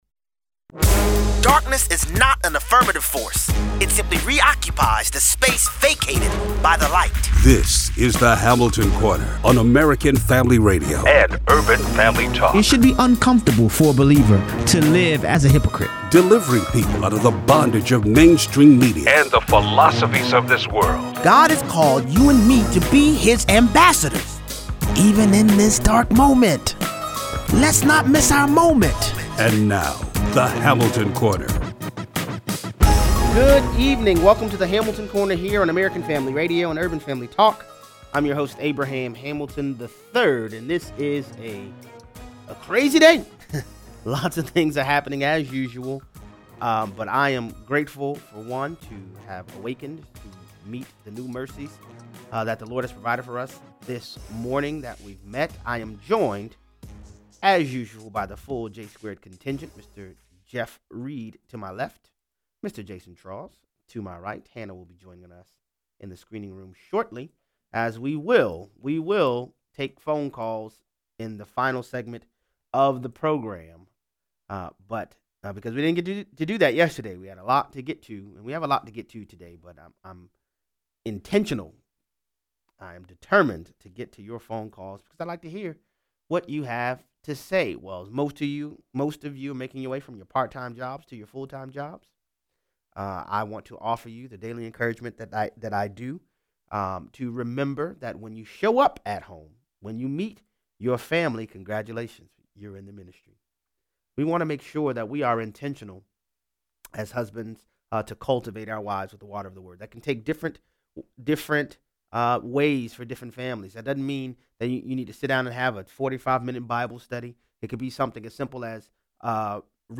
Fortify yourself against the manipulative efforts of talking snakes. 0:38 - 0:55: Joy-less Behar admits the reason the media runs with false narratives... they hate President Trump. Callers weigh in.